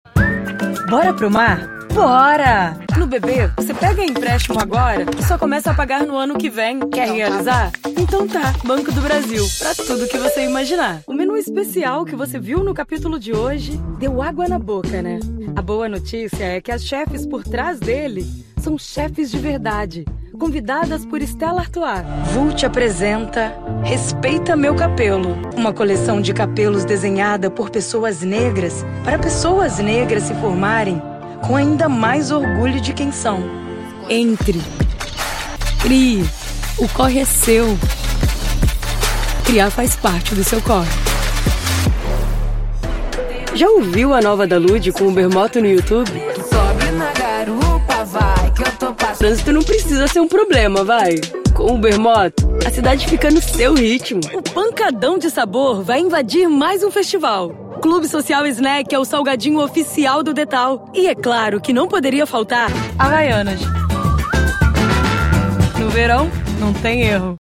Uma voz que traduz a essência das marcas!
Voz com um leve “rouquinho” pra deixar seu anuncio com um toque único, trazendo mais sofisticação e visibilidade no mercado publicitário.
Home studio com alta qualidade de som, comprometimento com entregas no mesmo dia e respostas a novos projetos em minutos via whatsApp.